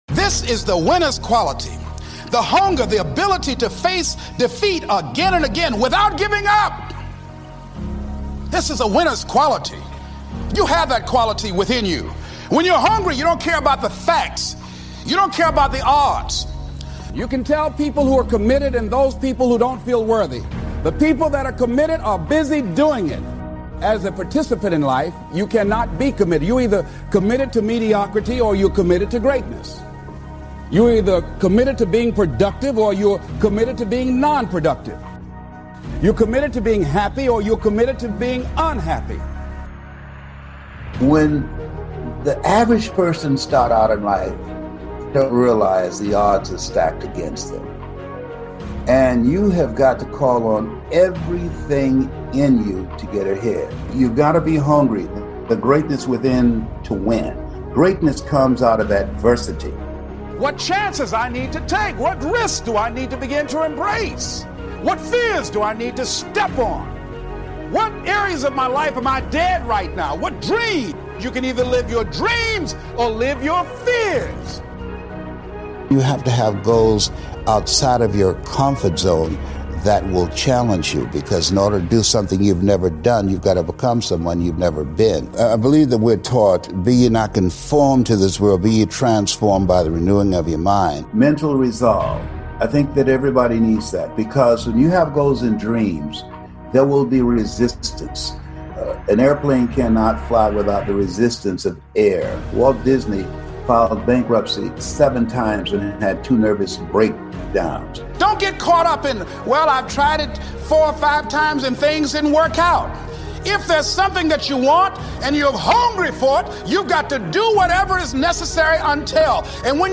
Les Brown - Commit yourself motivational speech
In this electrifying episode, motivational legend Les Brown delivers a life-changing call: commit yourself fully—no half-measures, no back doors, no Plan B. With his signature passion and powerful storytelling, Les draws from his own journey—from being labeled “educable mentally retarded” as a child to becoming one of the world’s most sought-after speakers—to show what happens when you make an unbreakable commitment to your dreams.